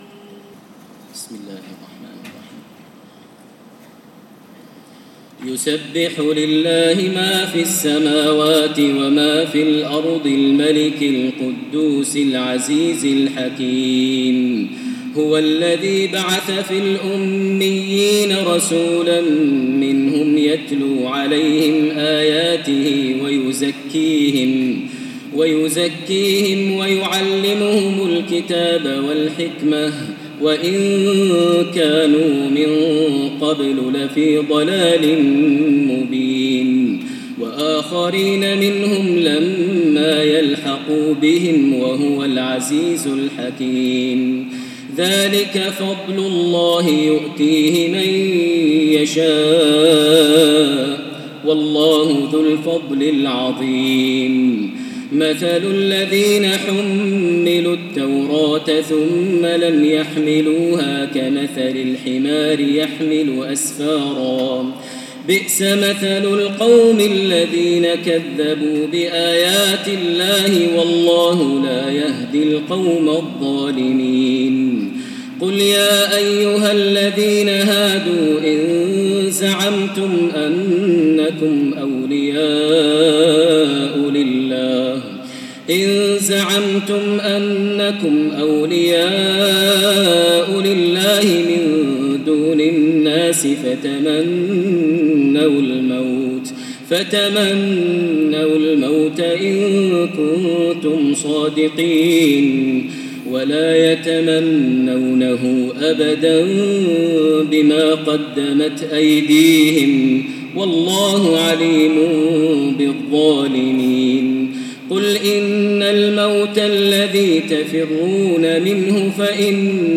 اهداء - من سورة الجمعة إلي سورة التحريم تراويح ليلة ٢٧ رمضان ١٤٣٧هـ ( *تسجيل نادر مؤثر من داخل* الحرم لفضيلة الشيخ د. ماهر المعيقلي)